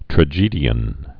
(trə-jēdē-ən)